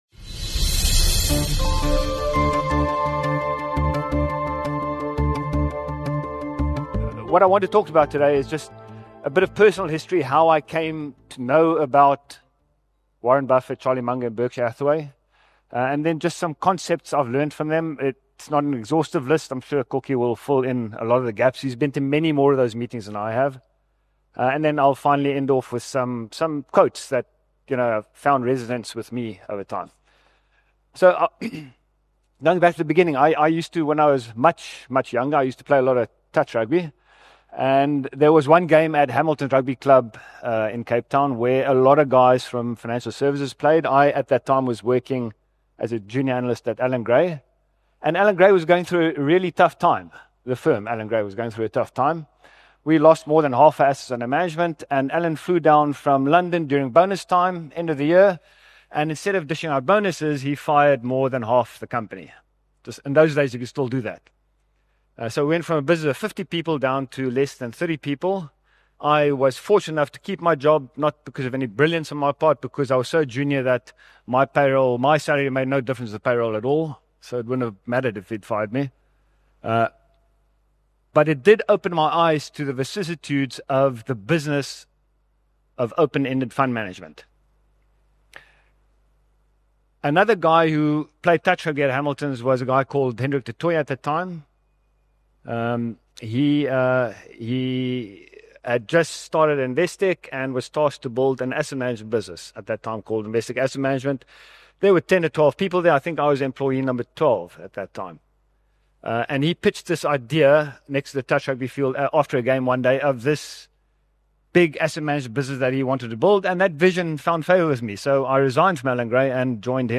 Together, they revealed timeless principles that built Berkshire into a titan—and shaped their own success. Following their keynote speeches
a lively Q&A session